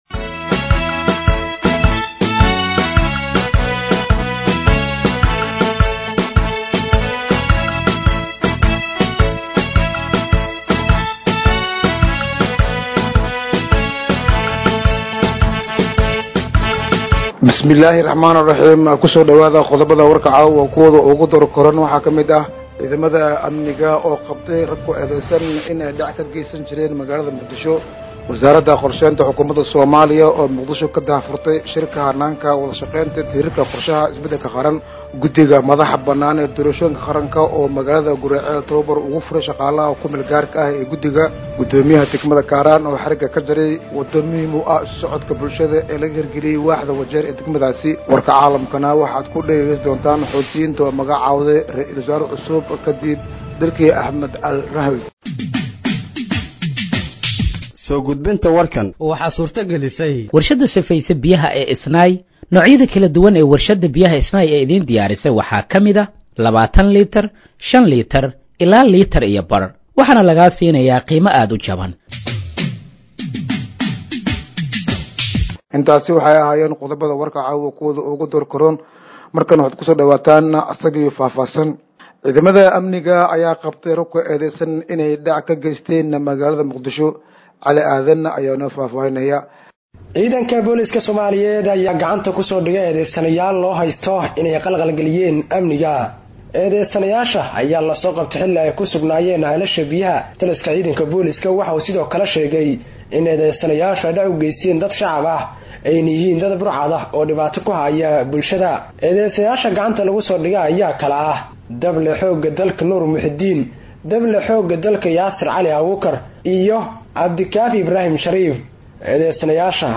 Dhageeyso Warka Habeenimo ee Radiojowhar 31/08/2025